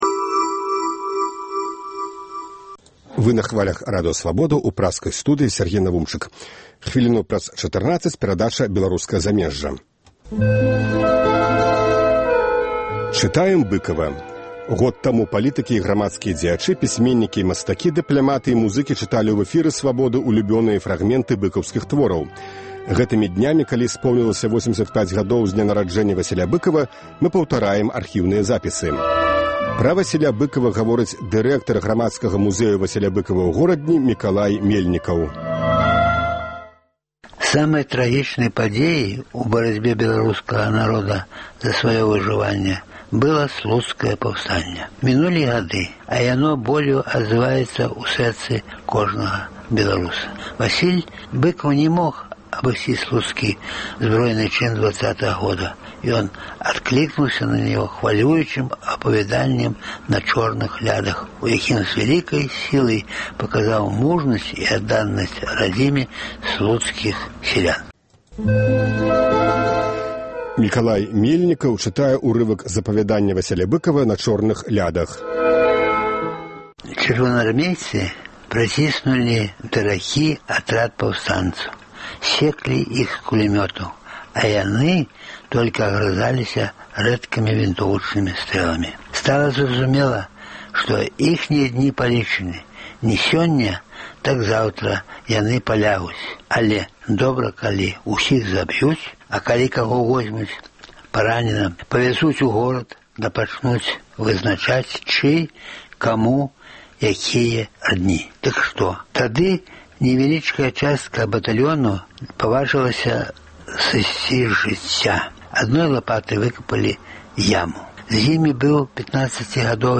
Вядомыя людзі Беларусі чытаюць свае ўлюбёныя творы Васіля Быкава.
Былы амбасадар Ізраілю ў Беларусі Зэеў Бэн-Ар'е чытае фрагмэнт з апавяданьня "Бедныя людзі".